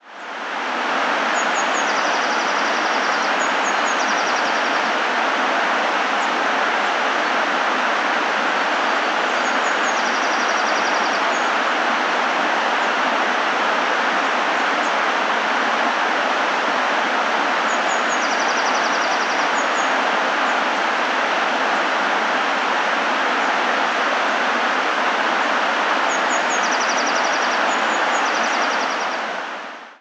Ambiente de bosque con río
ambiente
bosque
río
Sonidos: Agua
Sonidos: Animales
Sonidos: Rural